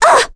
Ophelia-Vox_Damage_01.wav